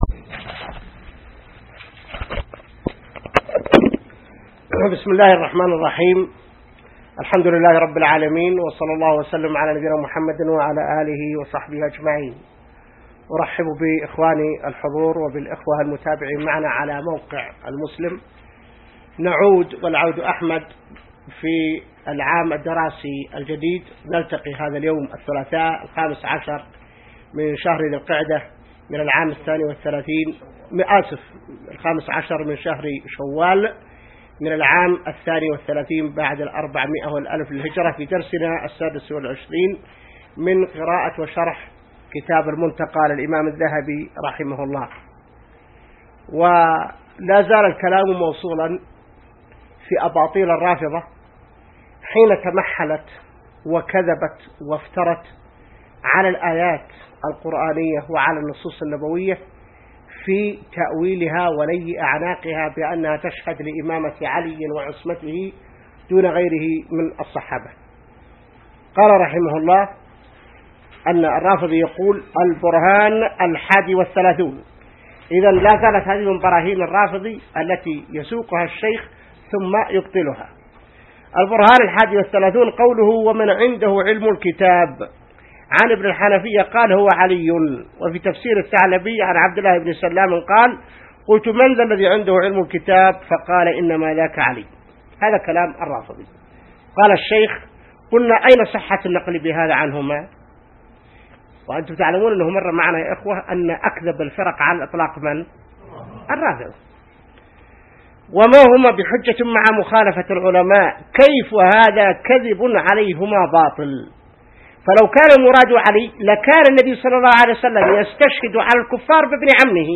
الدرس 26 من شرح كتاب المنتقى | موقع المسلم